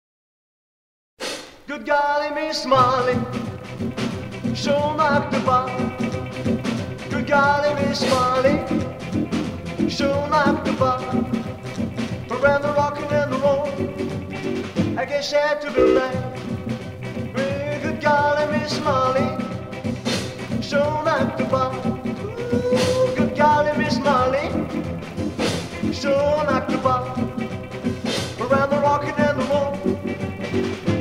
Rock'n'roll